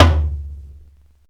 normal-hitwhistle.ogg